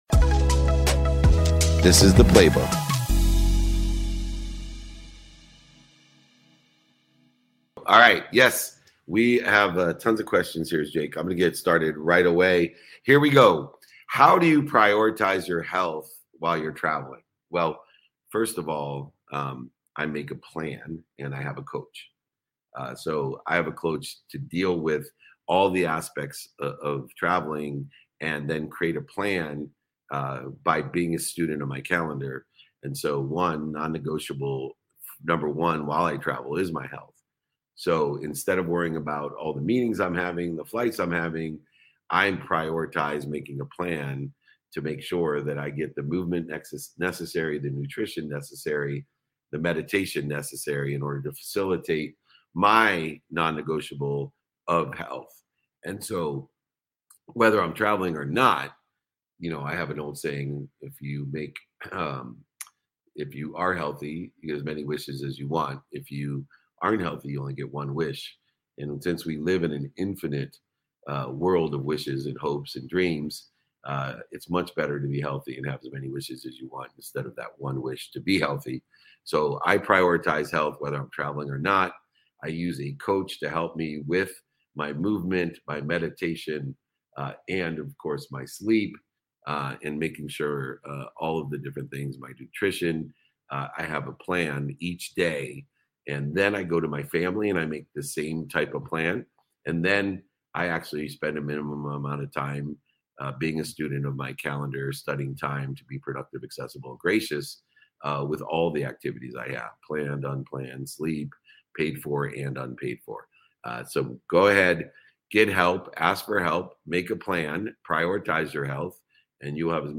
Each weekly training provides a new topic with a correlated training guide to provide you with pragmatic strategies to implement in your daily activity to achieve your goals. They take place live every Friday at 7 am PST/10 am EST via a webinar with LIVE Q&A submitted by attending guests.